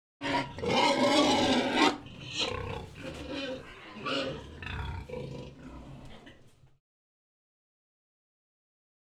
Index of /90_sSampleCDs/E-MU Producer Series Vol. 3 – Hollywood Sound Effects/Water/Pigs
PIGSTY-R.wav